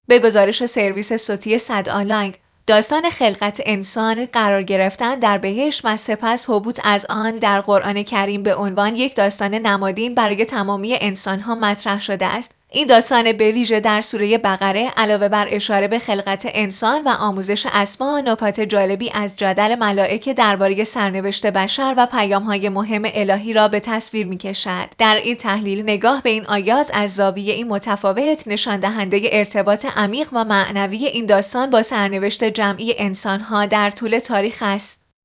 گفت‌وگویی داشتیم.